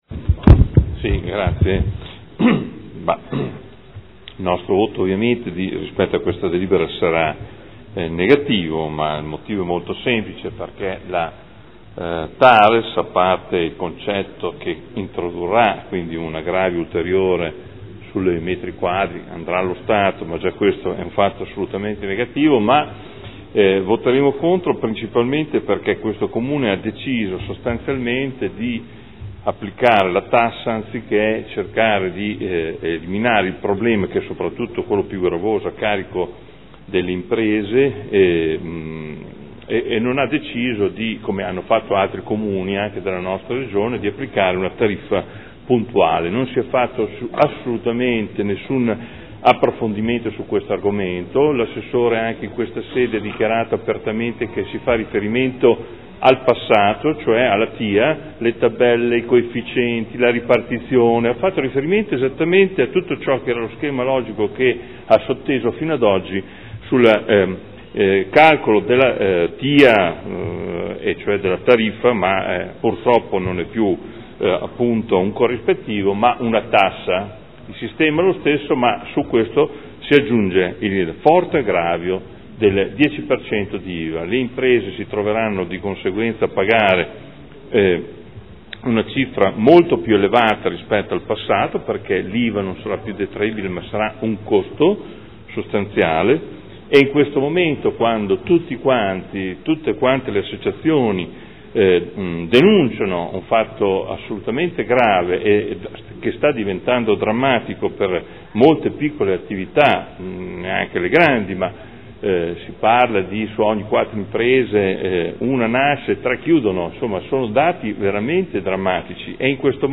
Adolfo Morandi — Sito Audio Consiglio Comunale
Dichiarazione di voto. Delibera: Tributo comunale sui rifiuti e sui servizi indivisibili – TARES – Approvazione delle tariffe, del Piano Economico Finanziario, del Piano annuale delle attività per l’espletamento dei servizi di gestione dei rifiuti urbani e assimilati